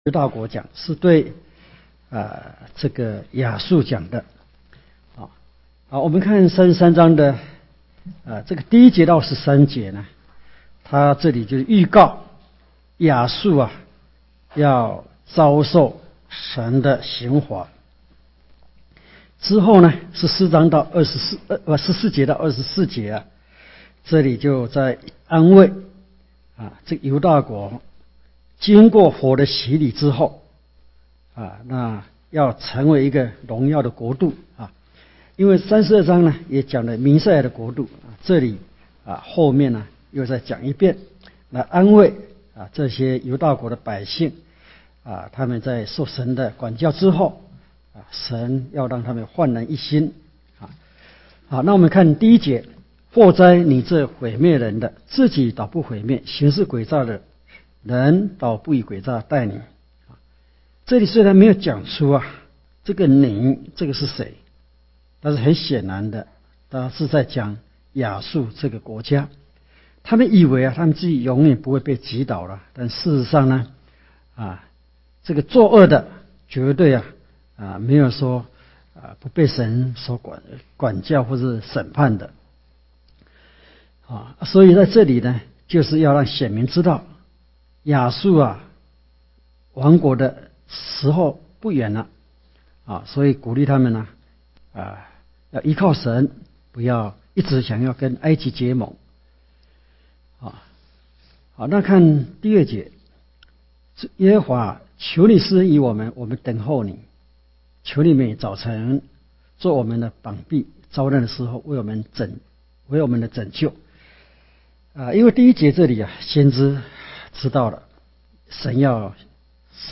講習會
地點 台灣總會 檔案下載 列印本頁 分享好友 意見反應 Series more » • 以賽亞書1-2章 • 以賽亞書3-6章 • 以賽亞書7-9章 …